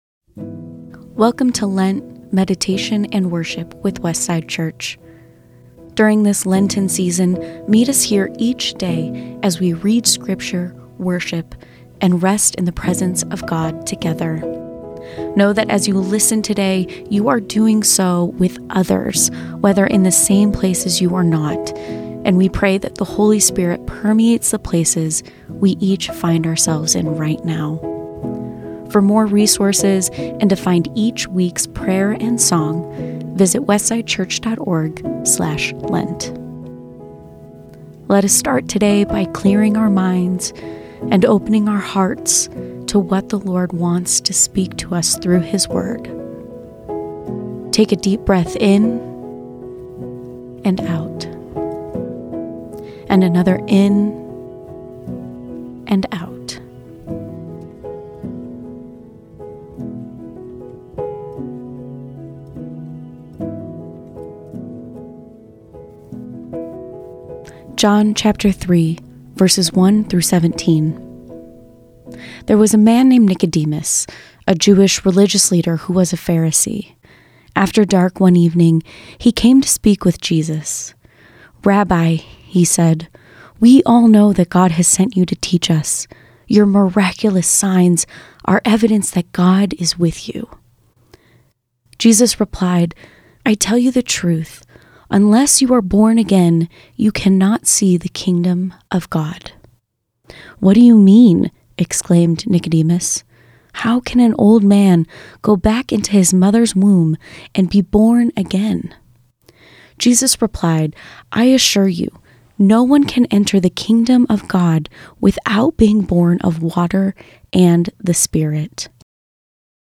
A reading from John 3:1-17 A prayer for your week: Hope beyond all human hope, you promised descendants as numerous as the stars to old Abraham and barren Sarah.